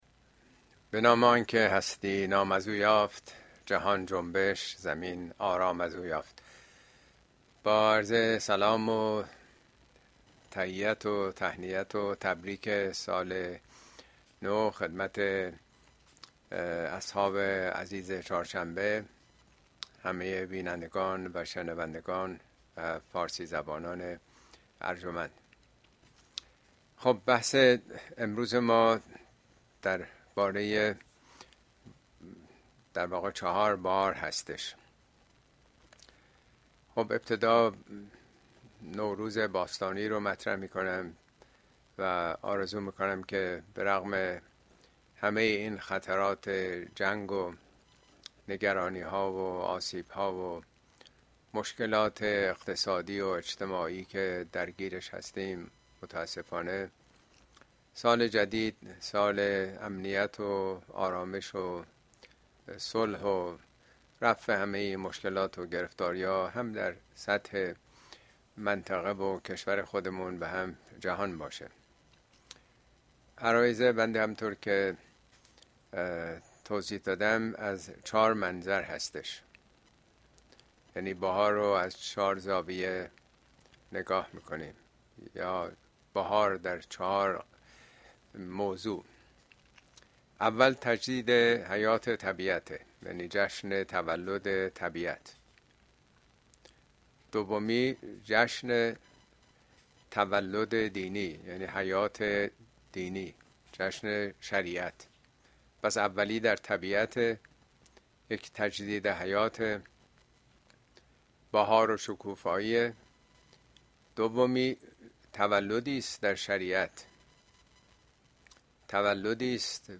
` خدا در طبيعت چهار بهار!؟ طبیعت، شریعت، ولایت و قیامت اين سخنرانى به تاريخ ۲۶ مارچ ۲۰۲۵ در كلاس آنلاين پخش شده است توصيه ميشود براىاستماع سخنرانى از گزينه STREAM استفاده كنيد.